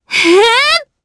Frey-Vox_Casting2_jp.wav